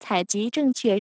采集正确.wav